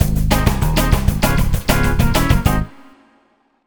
Swinging 60s 6 Full-G#.wav